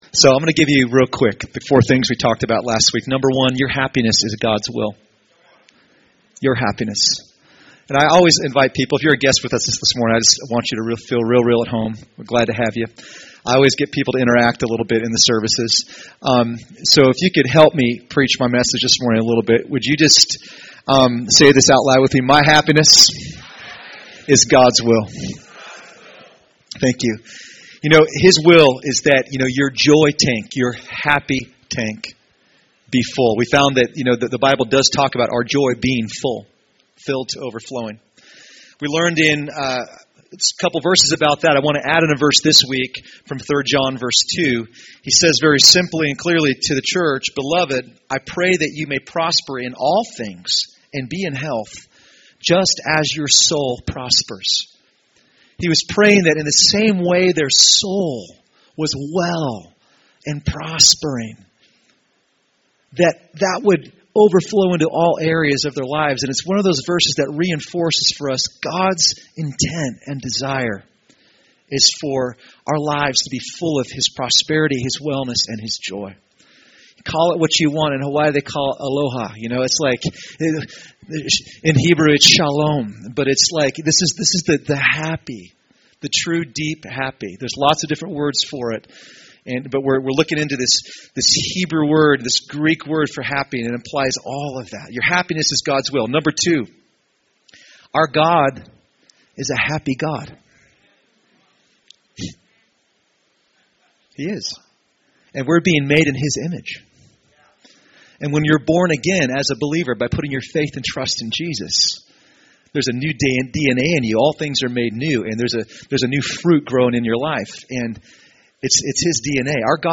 Recorded at New Life Christian Center, Sunday, February 8, 2015 at 11AM.